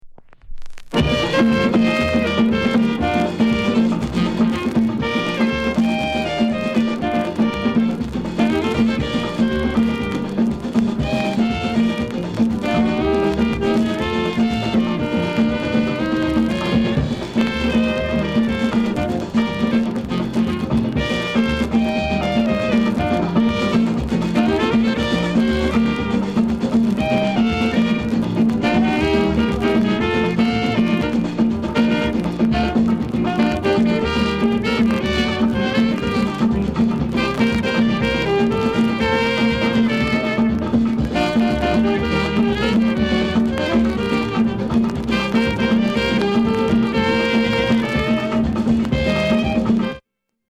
SOUND CONDITION A SIDE VG-
RARE CALYPSO